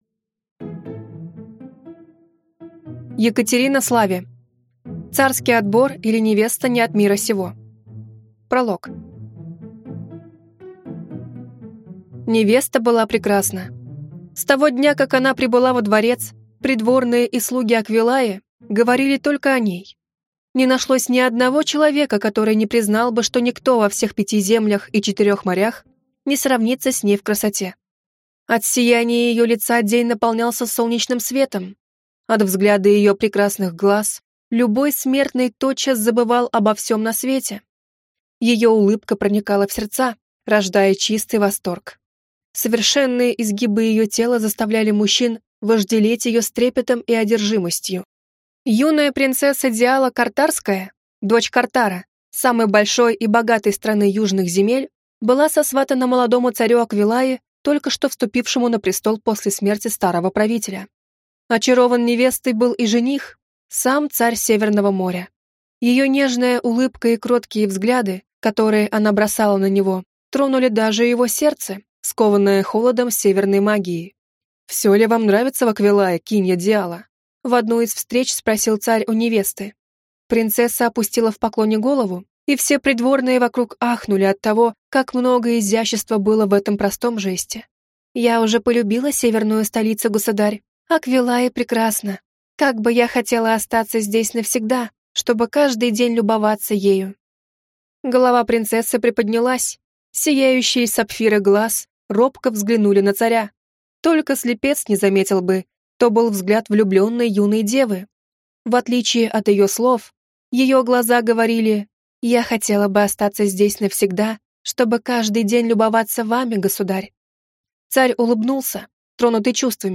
Аудиокнига Царский отбор, или Невеста не от мира сего | Библиотека аудиокниг